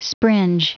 Prononciation audio / Fichier audio de SPRINGE en anglais
Prononciation du mot springe en anglais (fichier audio)